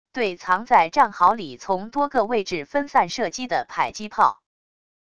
对藏在战壕里从多个位置分散射击的迫击炮wav音频